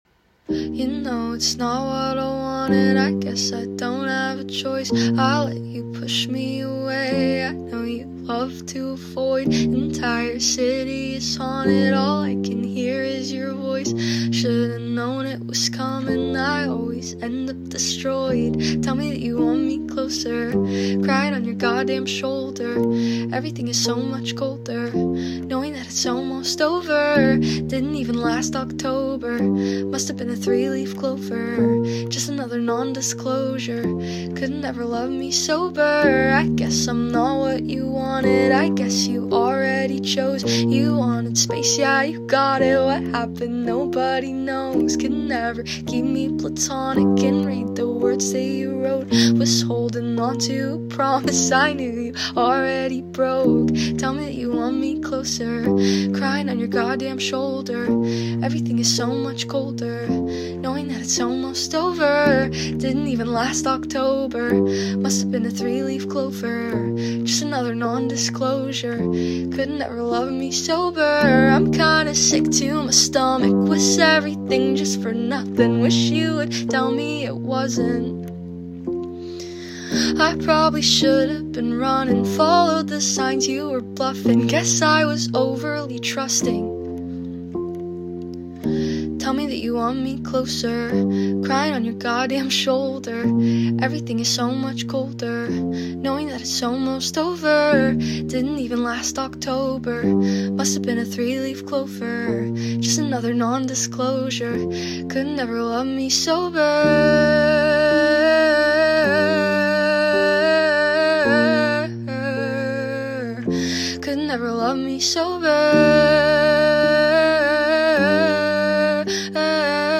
live/acoustic version